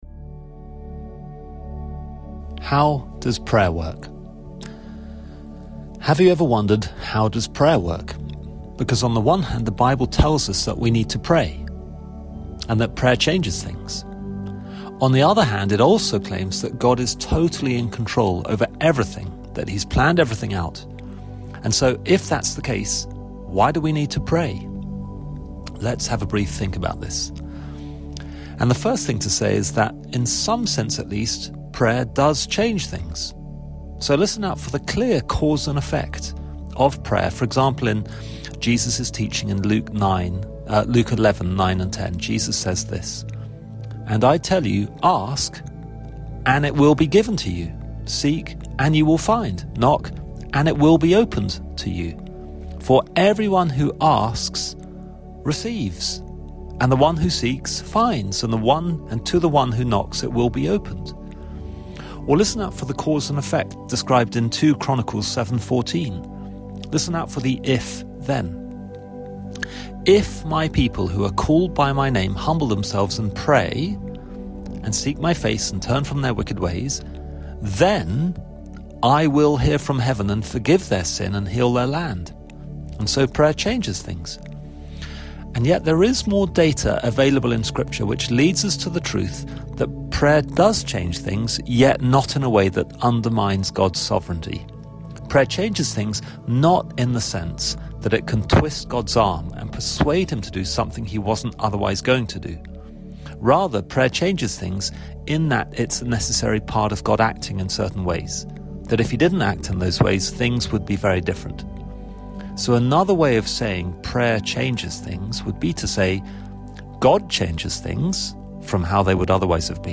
2025 Current Sermon How does prayer work?